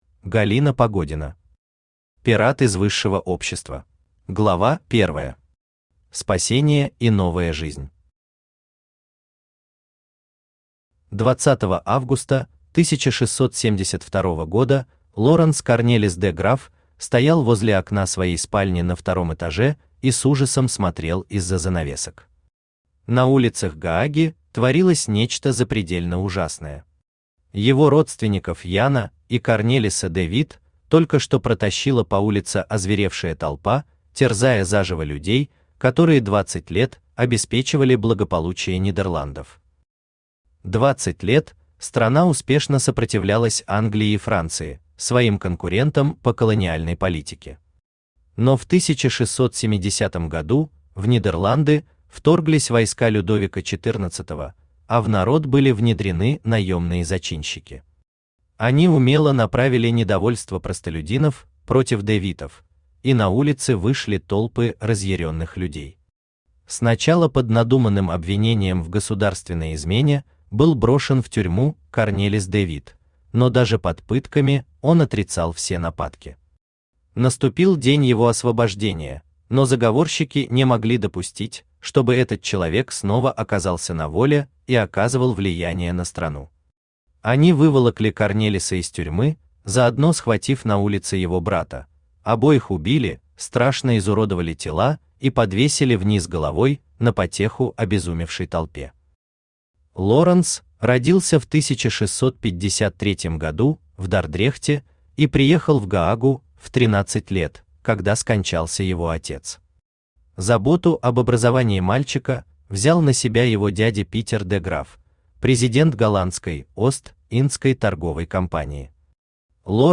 Aудиокнига Пират из высшего общества Автор Галина Вадимовна Погодина Читает аудиокнигу Авточтец ЛитРес.